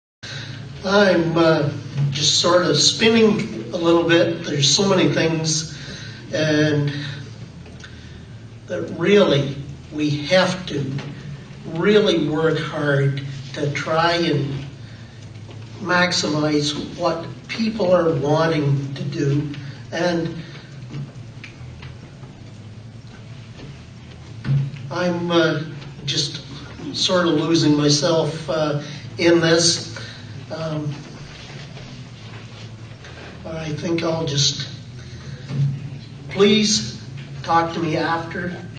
Recently North Huron Reeve neil vincent was asked where he saw North Huron in 5 years. Vincent offered a disjointed response void of content.